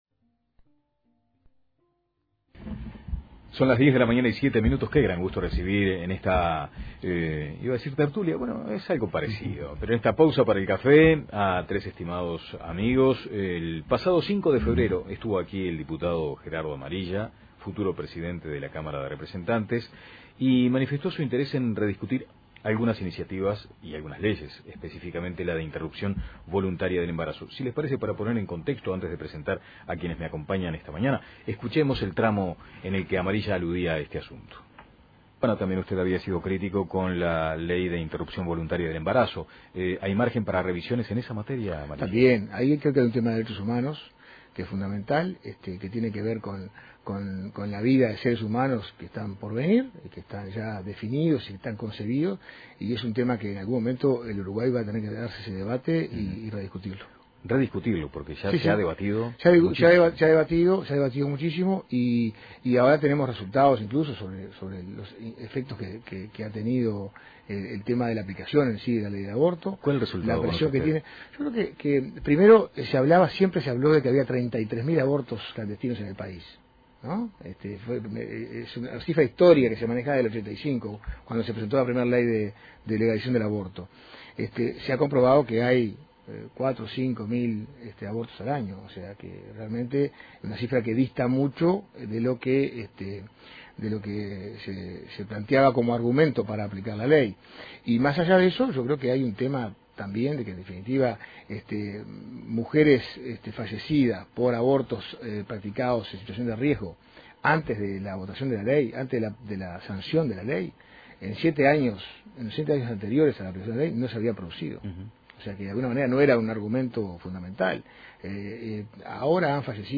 Los diputados Bertha Sanseverino (FA), Pablo Abdala (PN) e Iván Posada (PI) estuvieron presentes en La Mañana de El Espectador para debatir sobre la posibilidad o no de rediscutir la Ley de Interrupción Voluntaria del Embarazo.
Escuche aquí el debate completo: